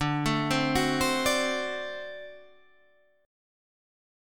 D7 Chord (page 2)
Listen to D7 strummed